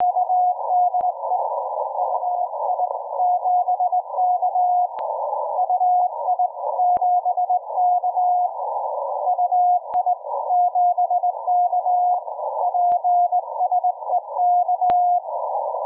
Сигнал мягкий, нежный и отлично читается.